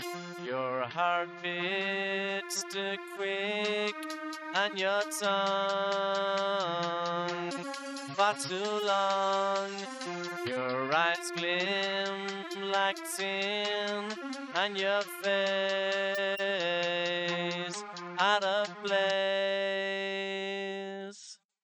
pop4.hf_.wav